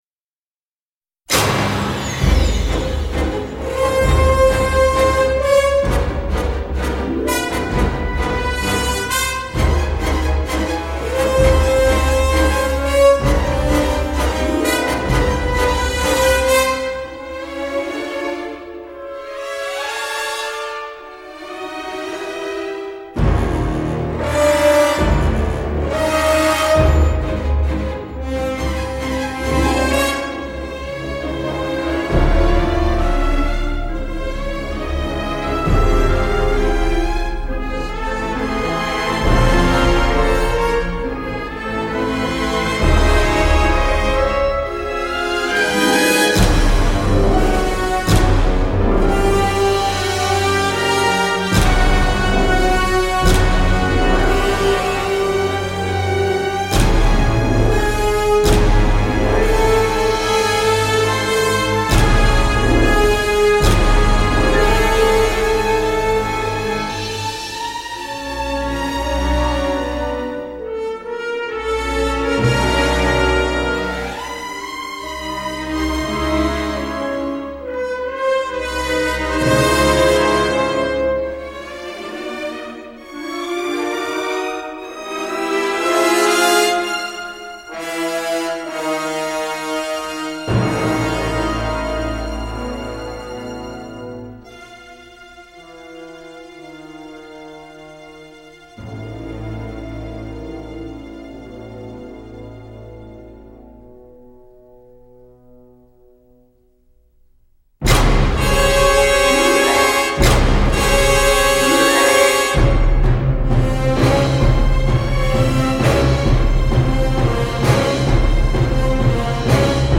basson, harmonica de verre et cordes notamment